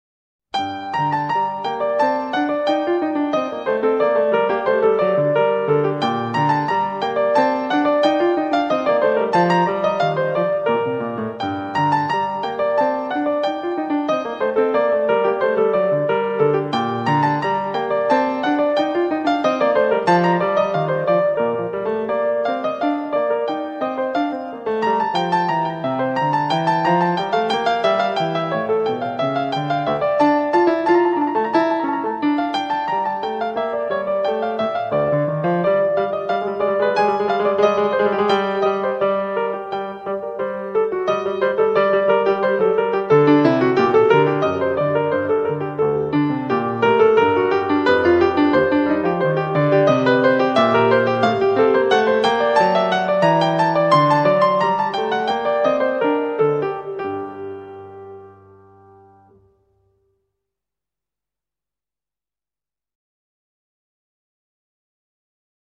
10 - G minor English Suite №3.mp3